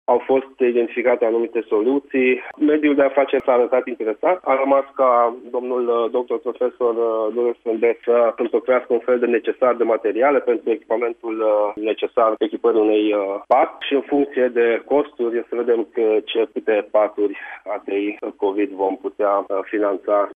În context, subprefectul de Timiș, Andrei Molnar, a precizat la Radio Timișoara că prin suplimentarea la nivel local a locurilor pentru pacienții infectați cu virusul SARS-CoV-2 sunt evitate procedurile birocratice de transfer al bolnavilor la spitale din alte județe sau chiar din străinătate.